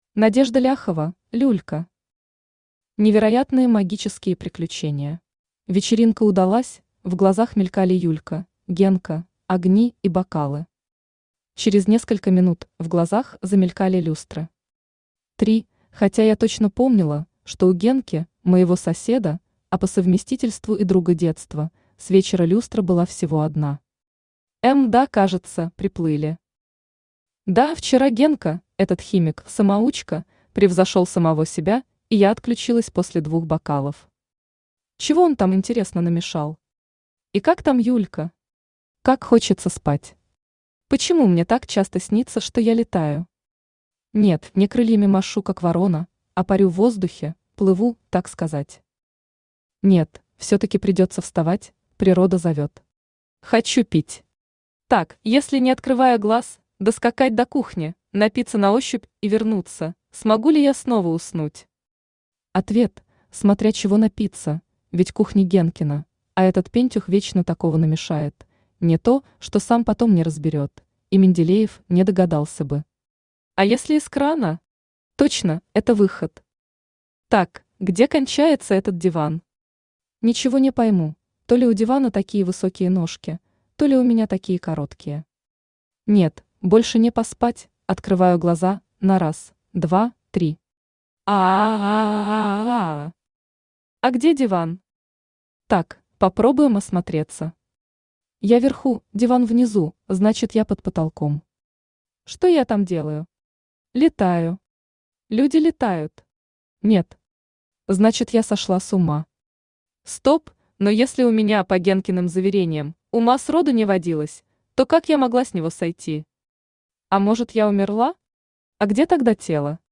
Аудиокнига Невероятные магические приключения | Библиотека аудиокниг
Aудиокнига Невероятные магические приключения Автор Надежда Викторовна Ляхова-Люлько Читает аудиокнигу Авточтец ЛитРес.